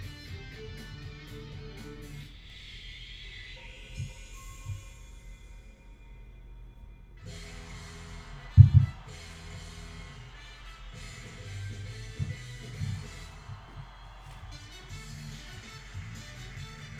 Das Blue Microphones Yeti USB-Mikrofon verwende ich, um die Geräusche im Wohnzimmer aufzunehmen Die Geräusche sind vor allem im Wohnzimmer deutlich zu hören, sie grenzt an die Küche an in der es zur Verlegung der Steigleitung kam.
Trittschallereignisse
In diesem Fall wurde in ruhiger Umgebung aufgenommen, daher zeigt die linke Aufnahme vom 15.10.2014, 19.54 Uhr 10 sec nur ein Rumzappeln um die Nullinie auf niedrigem Pegel, bis es zum eigentlichen Ereignis kommt.
Es sind ohne Vorwarnung, unvermittelt auftretende Trittschallereignisse. Ein hoher Pegel wie in diesem Fall wird nicht immer erreicht, aber es sind laute, deutlich hörbare Ereignisse, die jede Lebensqualität in meiner Wohnung durch die ständige Präzenz dieses Trittschalls zerstören.